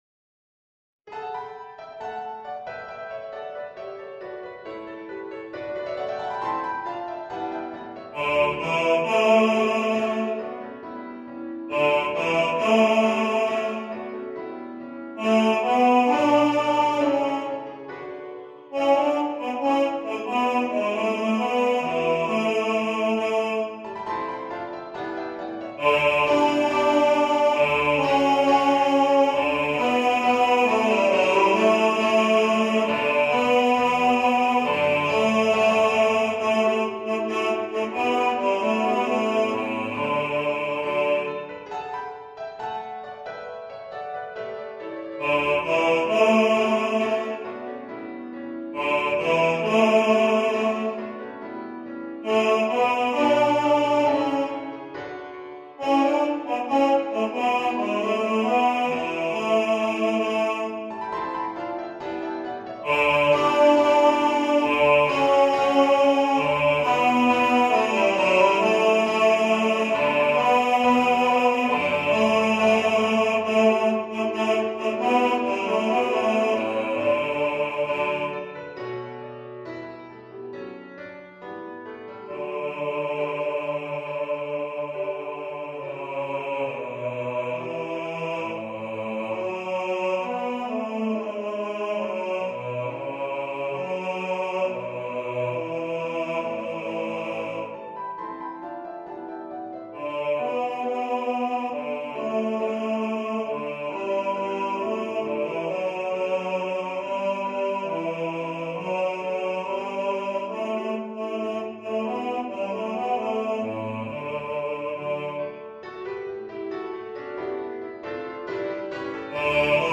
Star-Carol-Bass.mp3